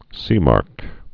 (sēmärk)